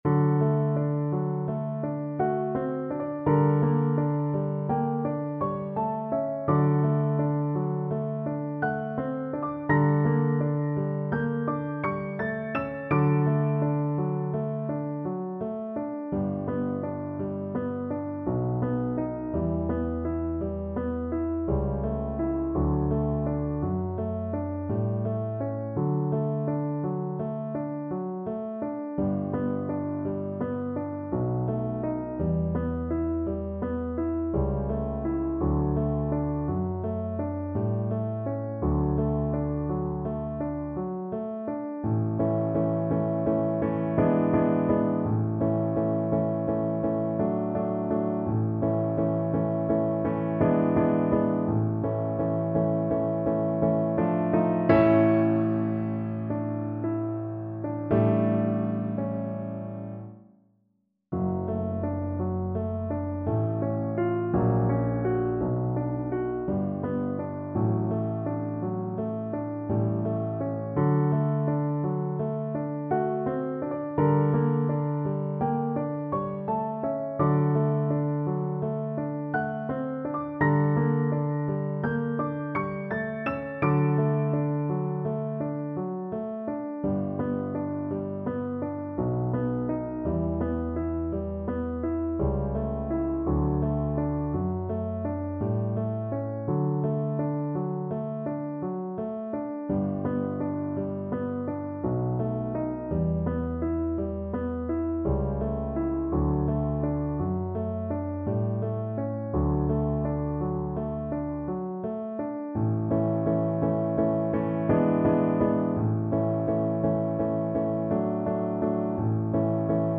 3/4 (View more 3/4 Music)
~ = 56 Ziemlich langsam
Classical (View more Classical Violin Music)